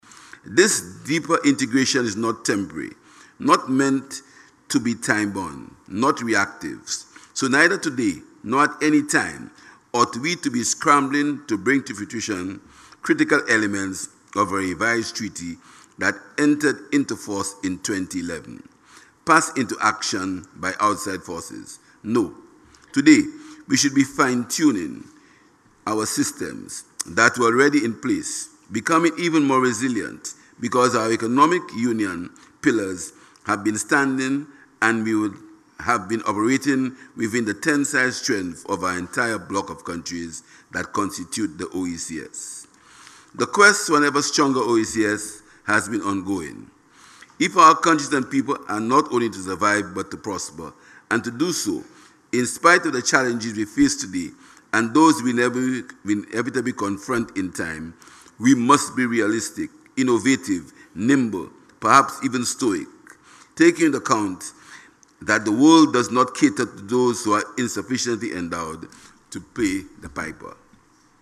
The 7 th Session of the OECS Assembly is being held at the Parliament Building in Calliaqua.